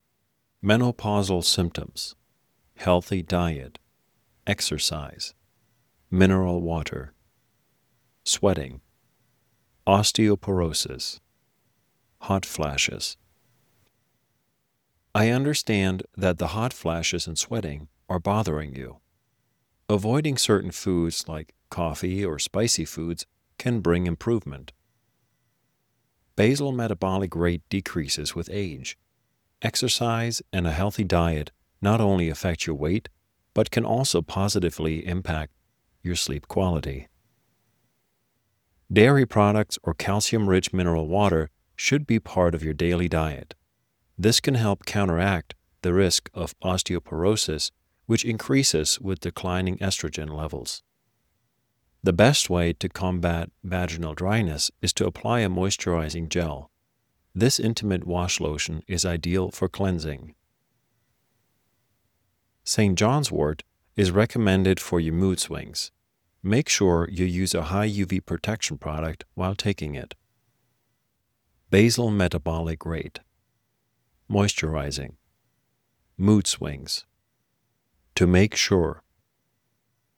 In dieser Englisch-Lerneinheit vermitteln wir Ihnen Wörter, die Sie rund um das Beratungsgespräch verwenden können, wenn es um das Thema Wechseljahresbeschwerden geht. Hören Sie in unserer Audiodatei, wie die englischen Wörter ausges...